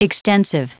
Prononciation du mot extensive en anglais (fichier audio)
Prononciation du mot : extensive